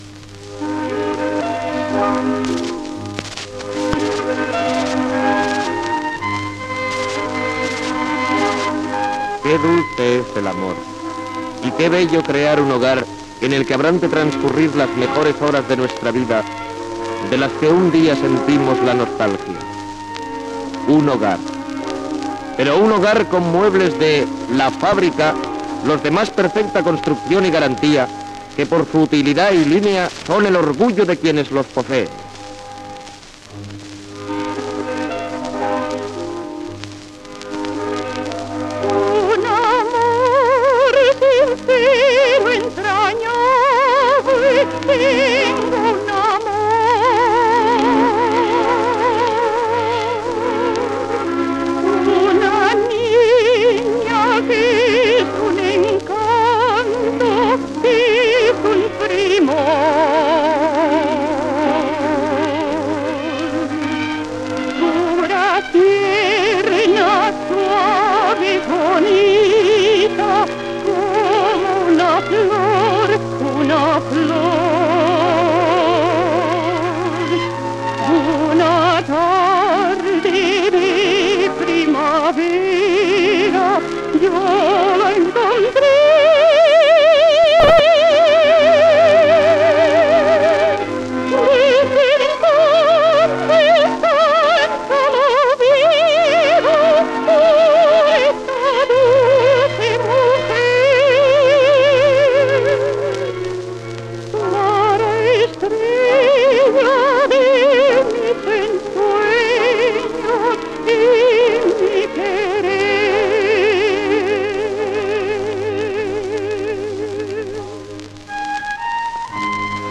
Publicitat de Muebles la Fábrica i cançó
El 12 de febrer de 1958 al Price de Barcelona es va celebrar el gran festival de Muebles la Fábrica dedicat als nuvis "El ahorro para el mueble" (1957) que va transmetre Ràdio Barcelona.